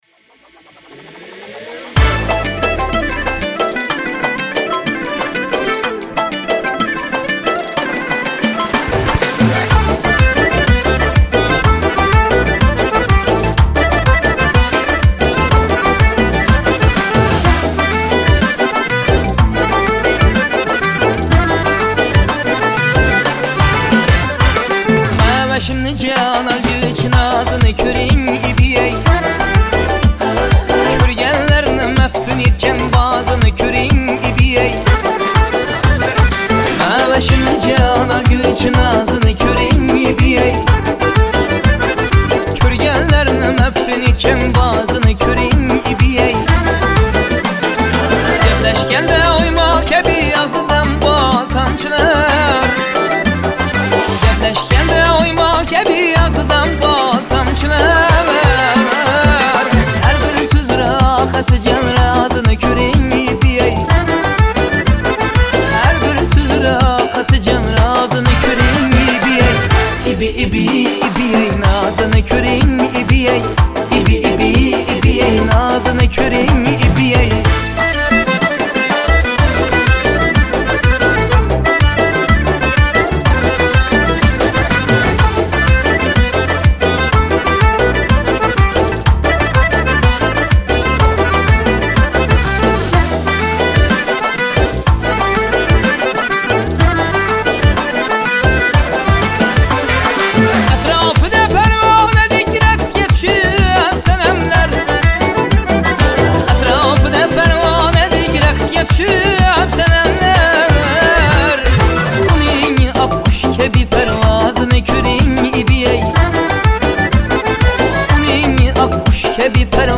• Жанр: Таджикские Песни